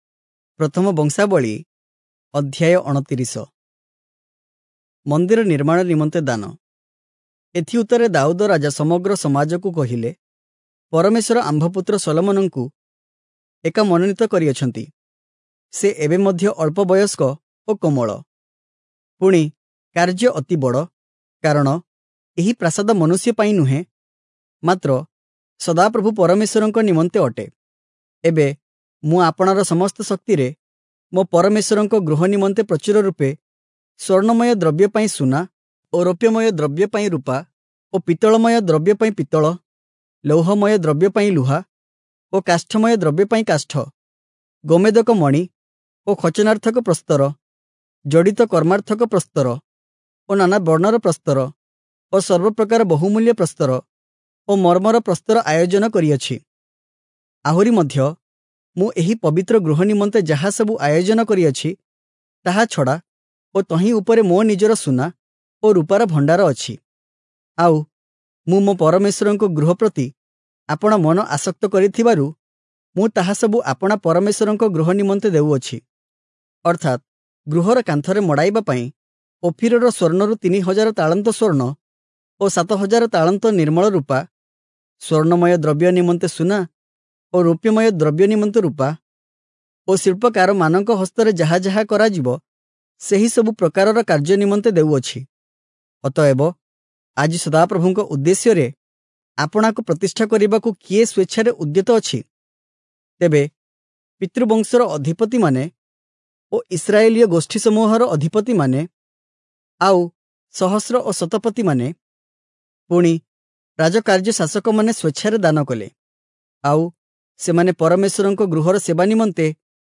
Oriya Audio Bible - 1-Chronicles 14 in Irvor bible version